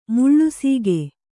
♪ muḷḷu sīge